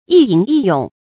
一吟一詠 注音： ㄧ ㄧㄣˊ ㄧ ㄩㄥˇ 讀音讀法： 意思解釋： 指吟詩作賦。